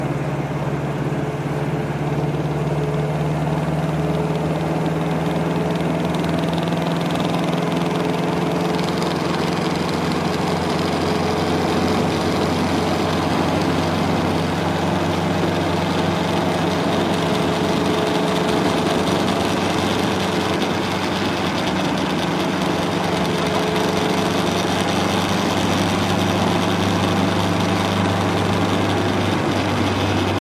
Et puis j’ai refait un tour avec, j’ai posé mon iphone sur le tunnel de BV et j’ai enregistré le bruit :
En accélérant on entend distinctement le tac tac qui, en insistant davantage, devient plus continu et davantage typé « ferraille » (j’étais à environ 80km/h et j’accélérais pour dépasser 90)
J'entends plutôt un clic clic qui semble éliminer les jeux de transmission effectivement.
Mga-tactac.mp3